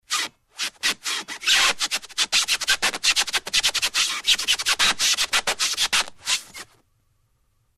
Rubber Squeaks And Friction, Like A Balloon Close Up, X2